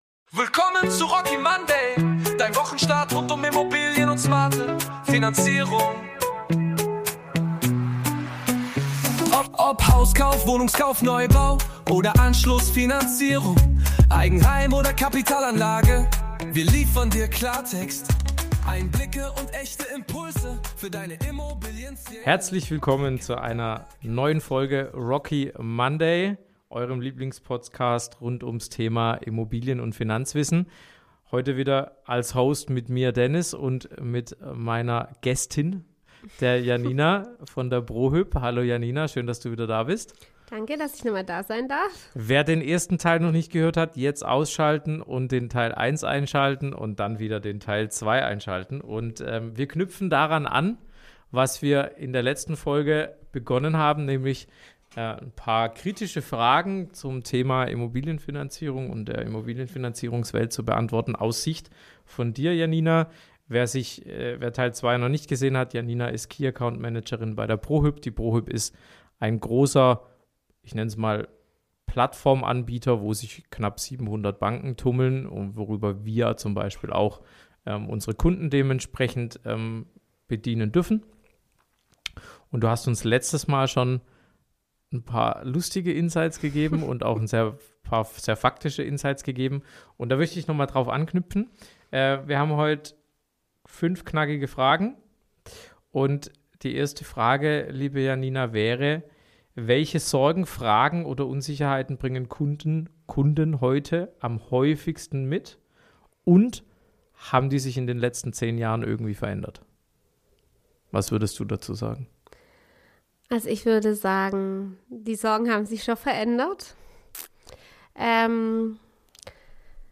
Ein offenes Gespräch über Entscheidungswege, Erwartungen, typische Missverständnisse und darüber, was gute Zusammenarbeit in der Finanzierung wirklich ausmacht.